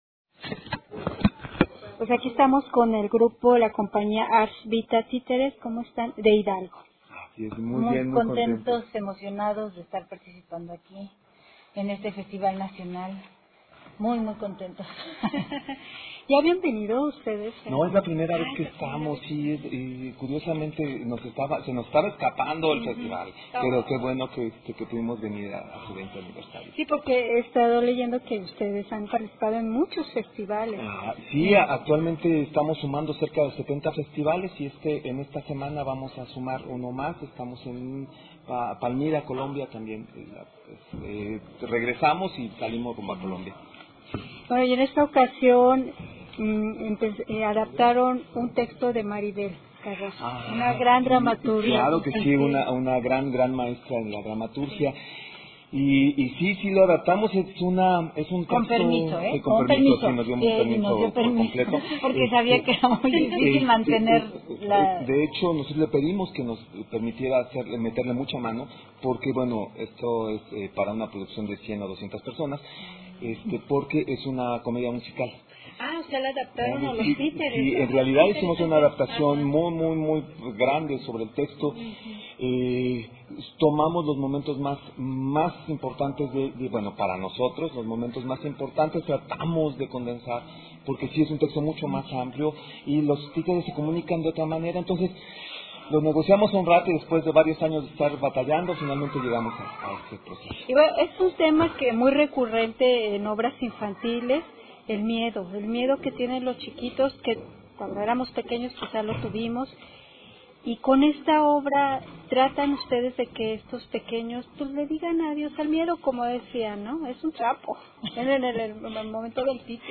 Entrevista con el elenco de la obra El ladrón de la música de la Compañía Arts-Vita Títeres